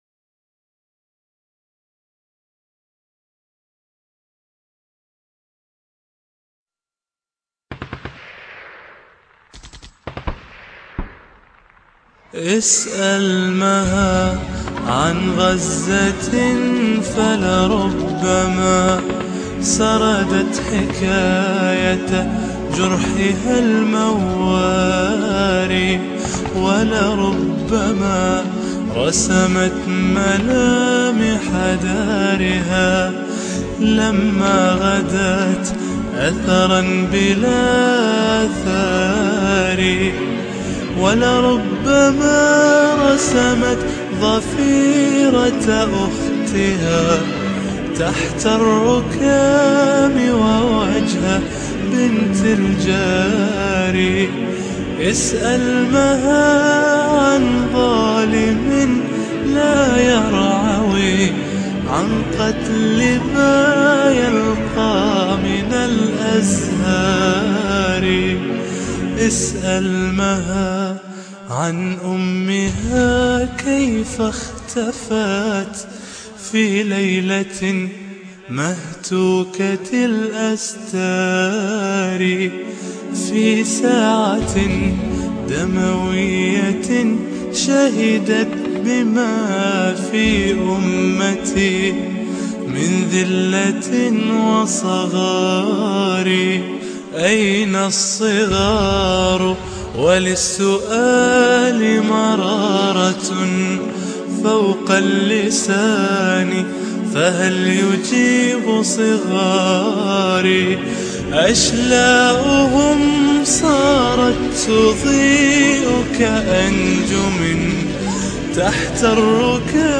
أقدم لكم انشودة
نشيدة جميله ،، ولها مضمون أجمل